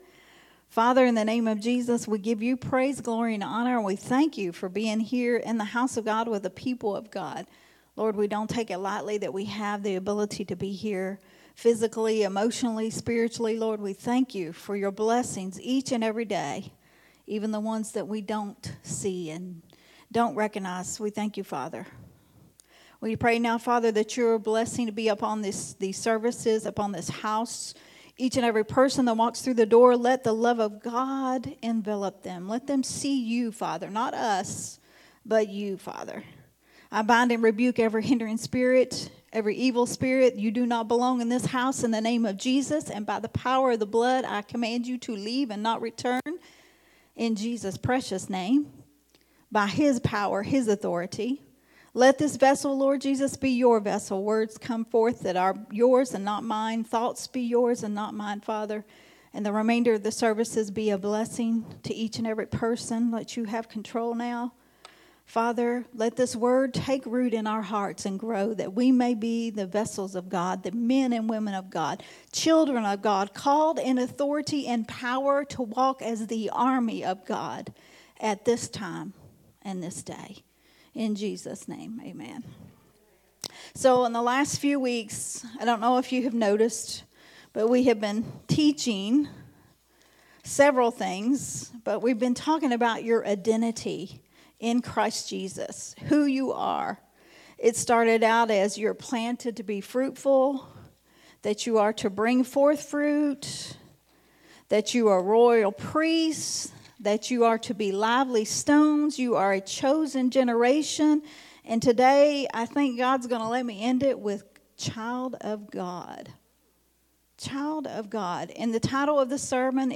Sunday Morning Refreshing